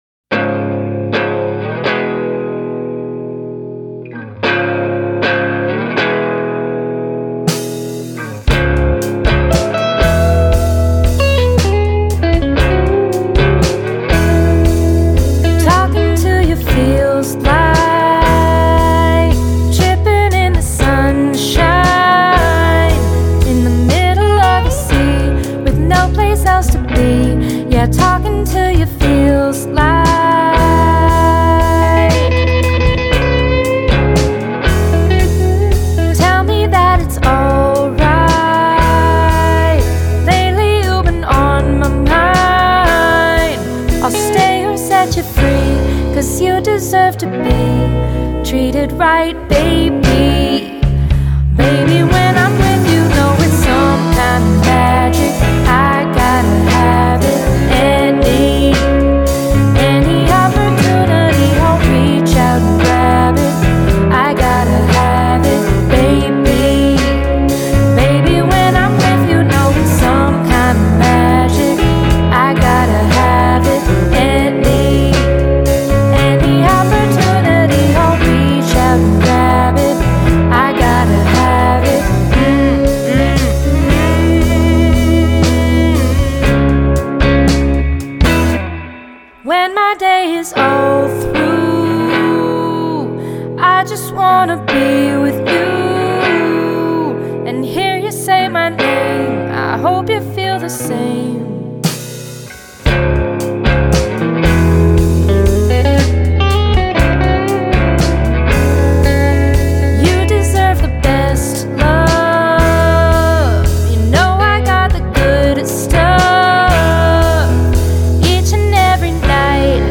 guitar
bass
drums, vocals, synths